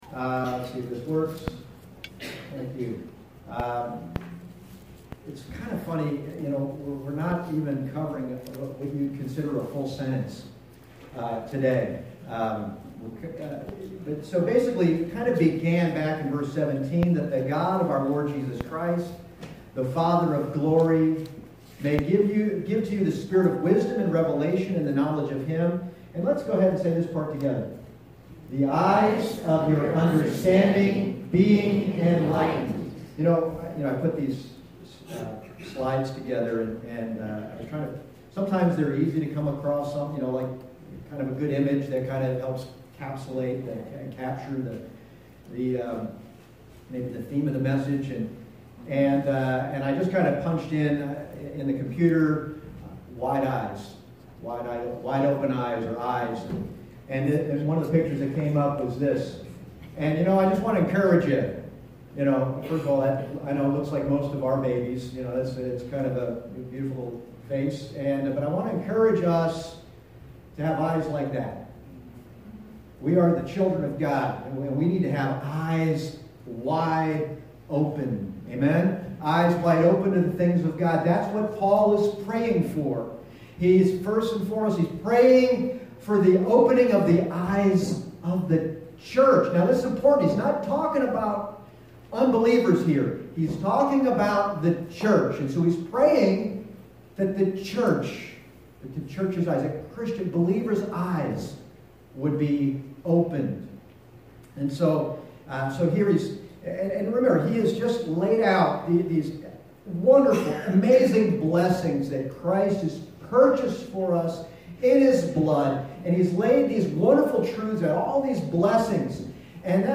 Passage: Ephesians 1:15-17 Service Type: Sunday Morning